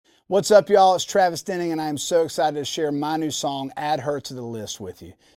LINER Travis Denning (Add Her To The List)
LINER-Travis-Denning-Add-Her-to-the-List.mp3